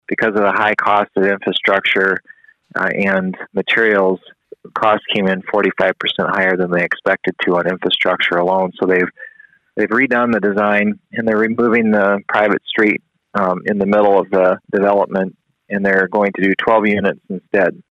Kooiker says a specific developer then later came into the picture with a proposal much different than the City originally expected.